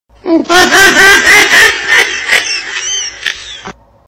Frog Laughing Meme Meme sound effects free download
Frog Laughing Meme - Meme Sound Effect